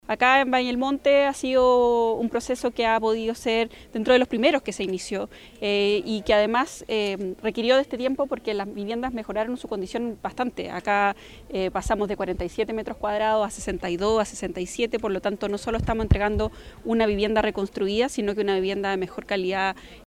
El proyecto contempla un total de 50 viviendas homogéneas, con áreas de estacionamiento y un aumento significativo en su superficie, pasando de 44 a 63 metros cuadrados, lo que mejora considerablemente la calidad de vida de los beneficiarios, según destacó la seremi de Vivienda.
cu-casas-quilpue-seremi.mp3